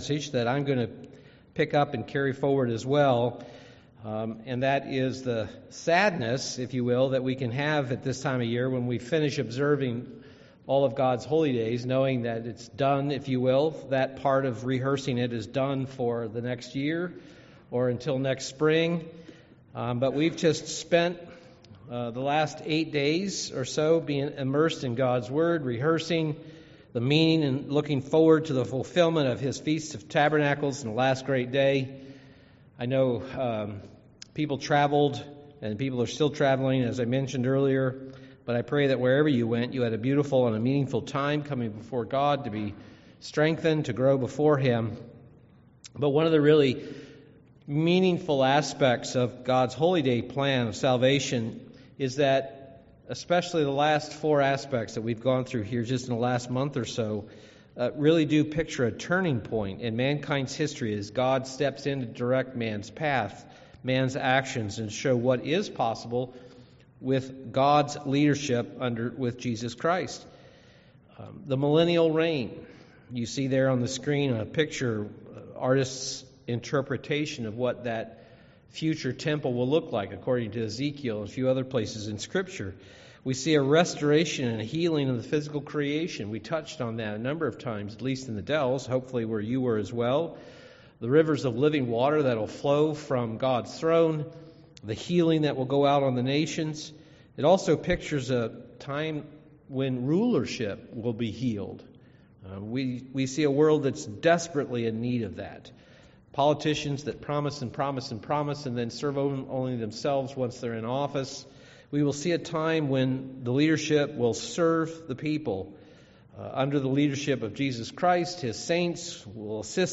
Given in Milwaukee, WI
UCG Sermon winter work Studying the bible?